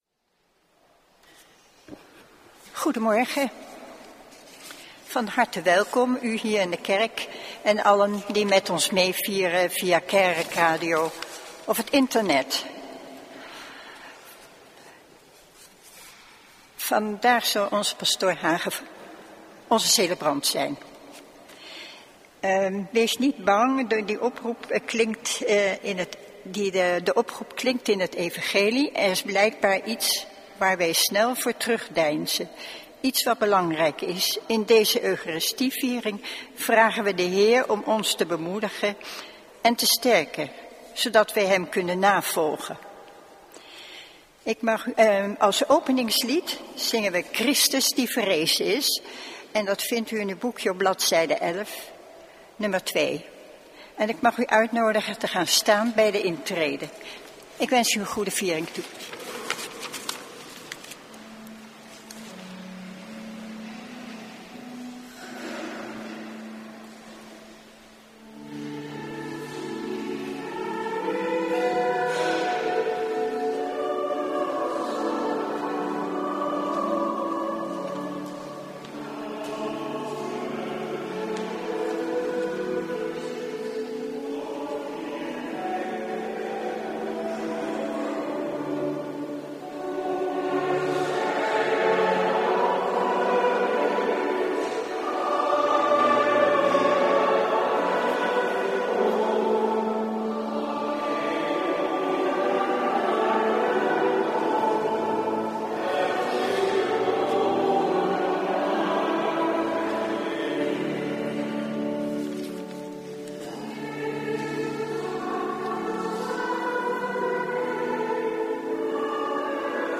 Lezingen